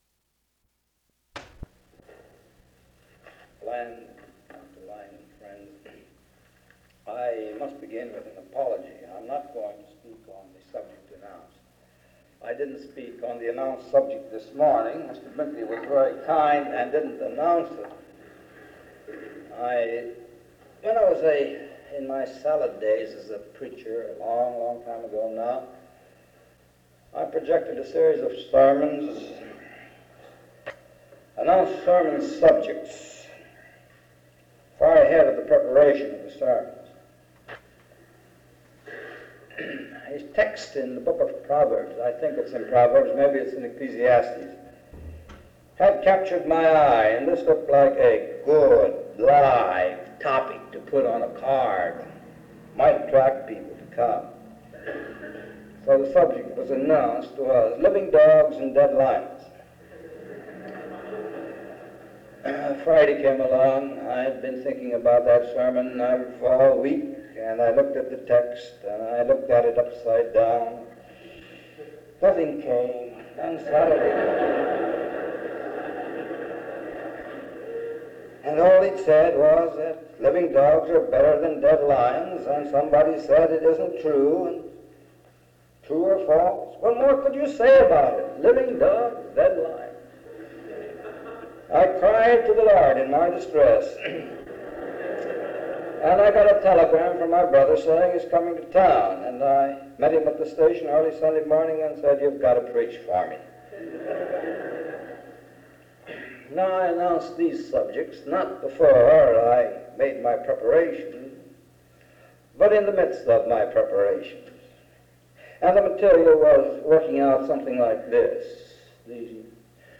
He spends most of this lecture comparing Protestantism to Democracy and sharing how they are so interconnected that they can at times become syncretistic.
In Collection: SEBTS Chapel and Special Event Recordings SEBTS Chapel and Special Event Recordings - 1950s Miniaturansicht Titel Hochladedatum Sichtbarkeit Aktionen SEBTS_Event_H_Richard_Niebuhr_1958-01-22.wav 2026-02-12 Herunterladen